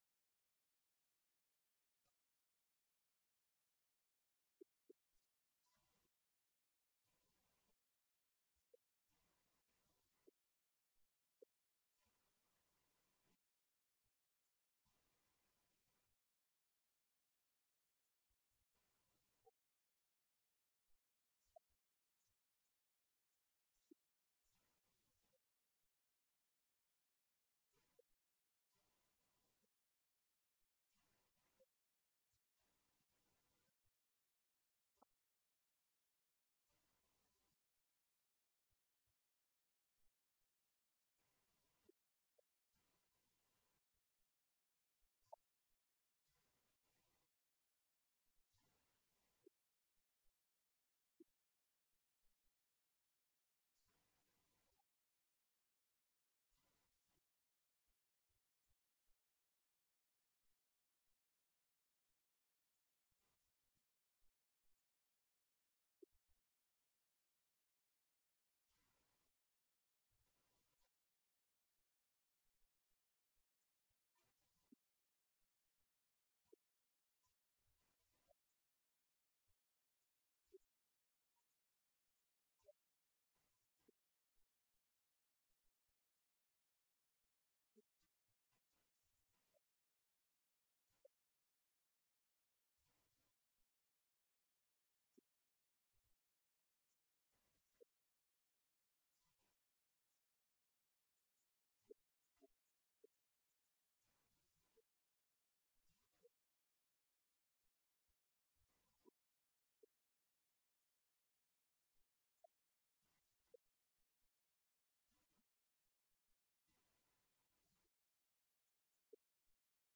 Event: 2019 Men's Development Conference
lecture